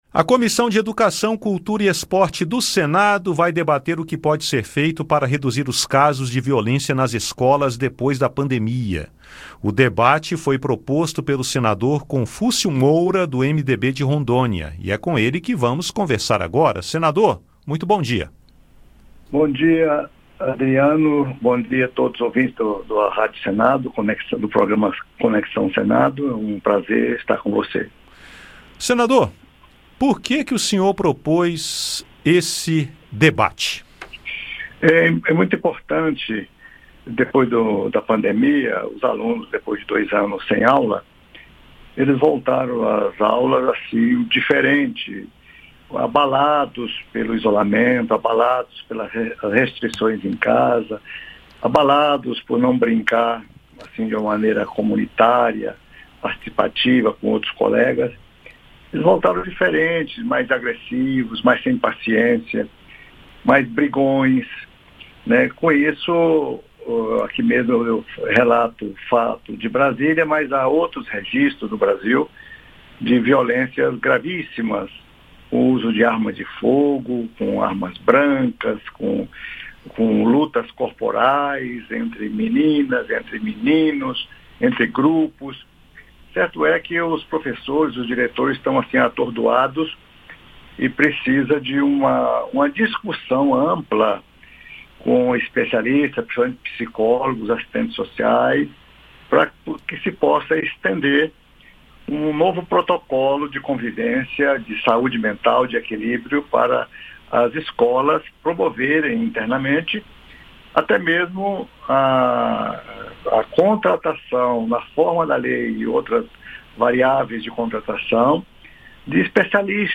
Confúcio Moura fala ao Conexão Senado sobre o que motivou o debate e afirma a importância de cuidado neste momento pós-pandemia, em que os alunos voltam às escolas e apresentam abalos e agressividade.